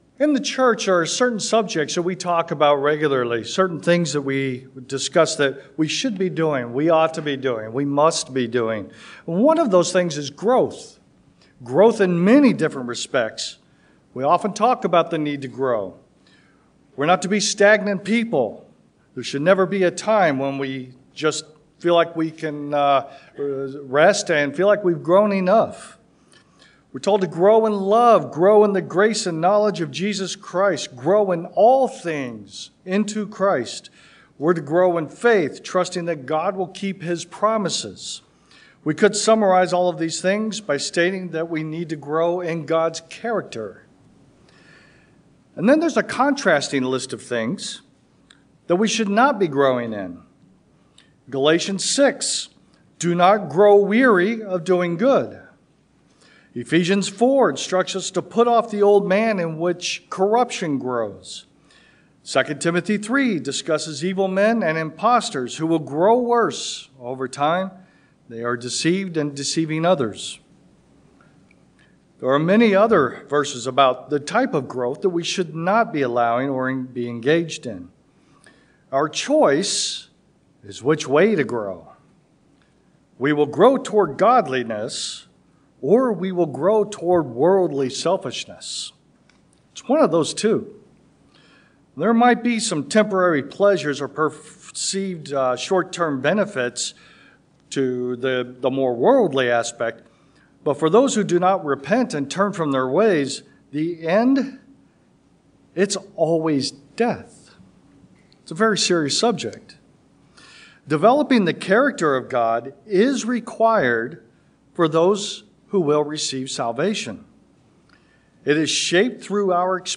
This sermon explores the need for and impact of Godly character on the use of authority. Several biblical examples showing both successes and failures are used to demonstrate that Godly authority is moderated by love, self-control, and a willingness to sacrifice for the unity and growth of others.